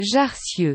Jarcieu (French pronunciation: [ʒaʁsjø]
Fr-Jarcieu-GT.wav.mp3